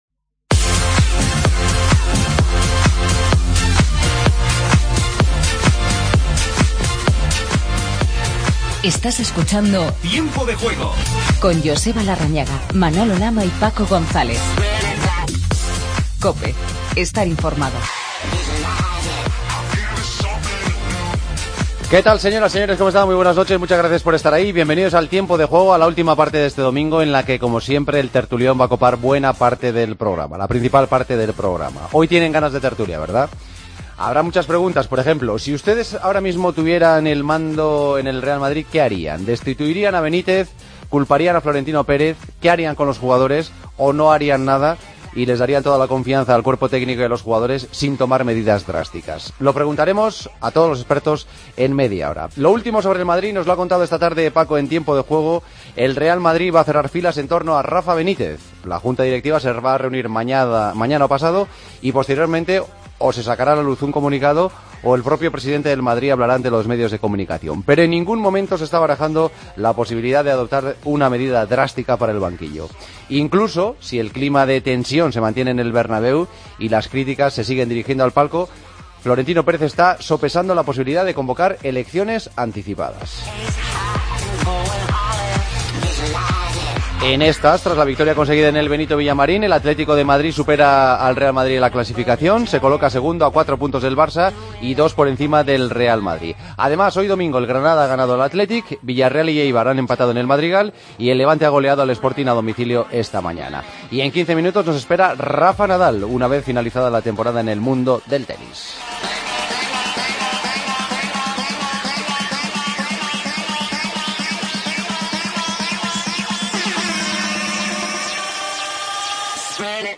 El día después del Clásico para Madrid y Barcelona. Hablamos con Filipe Luis, jugador del Atlético de Madrid. Repasamos la jornada 12ª de la Liga BBVA.